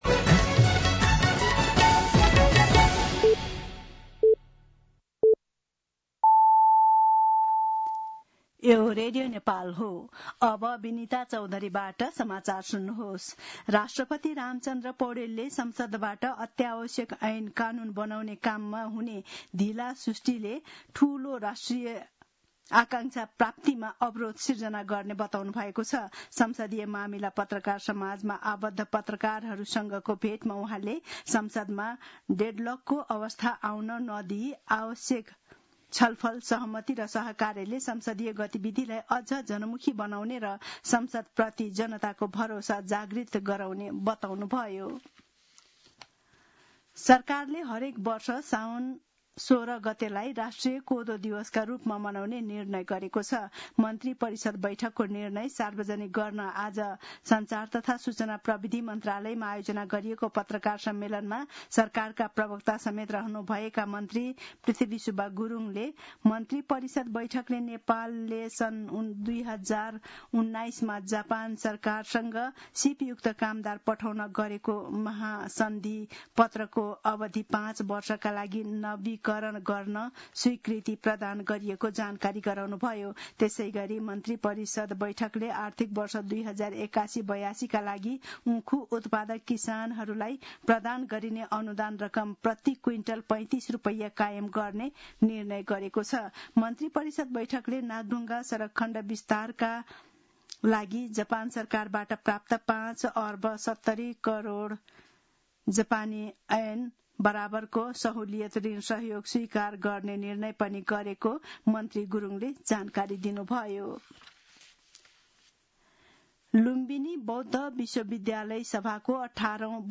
दिउँसो ४ बजेको नेपाली समाचार : ३२ असार , २०८२